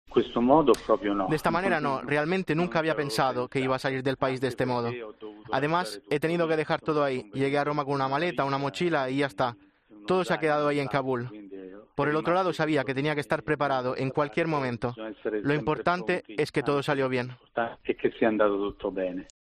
su voz emocionada recuerda su vida allí con muchas limitaciones.